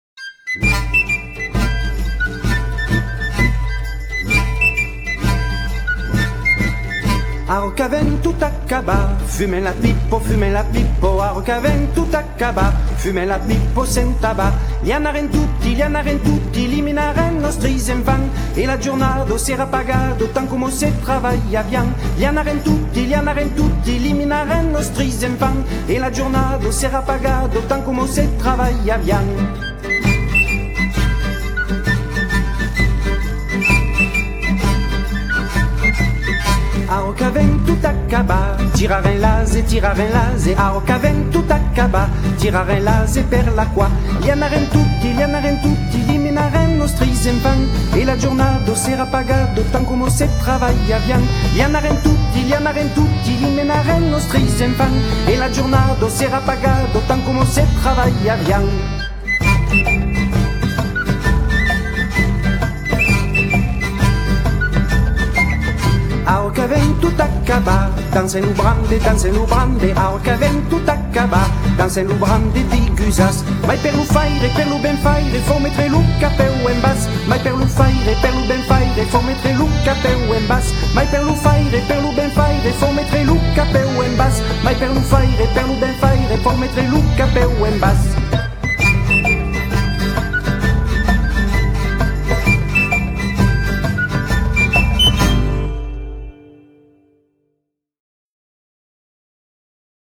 >> Provence : Le galoubet (questionnaire n°3 pour se préparer au second degré)
danse traditionnelle